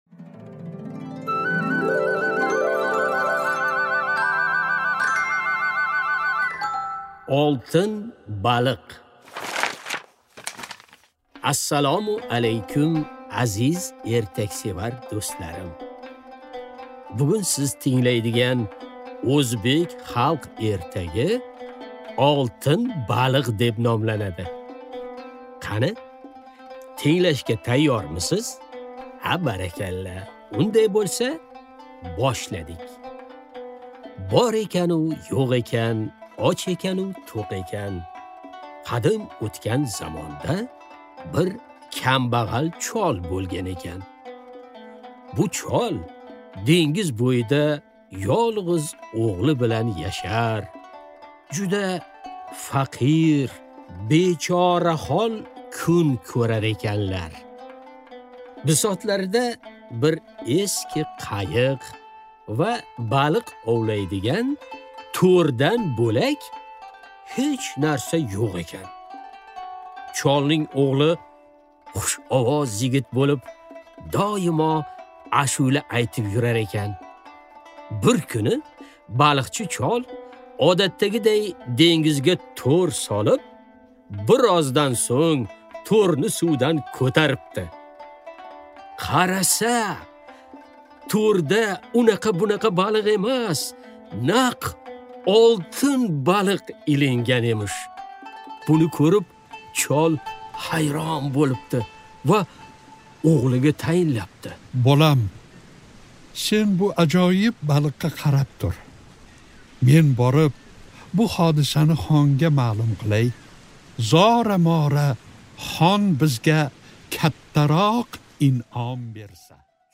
Аудиокнига Oltin baliq | Библиотека аудиокниг